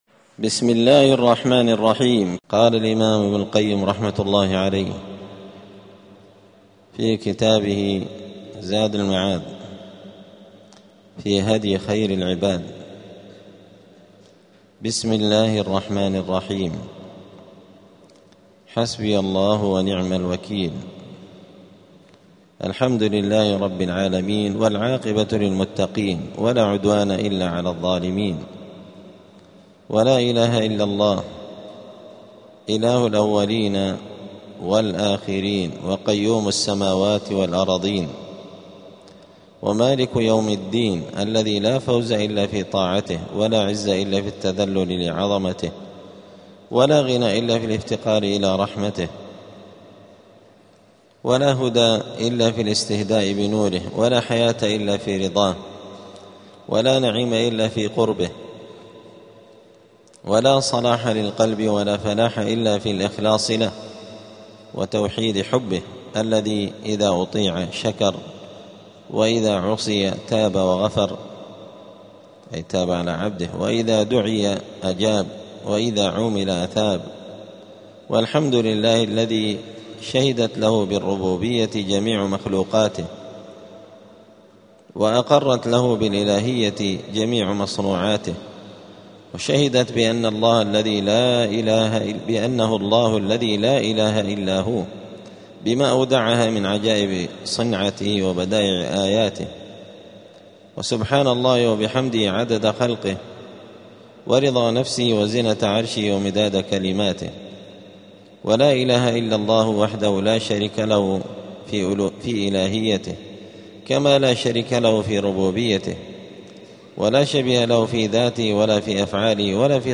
*الدرس الثاني (2) {مقدمة المؤلف}.*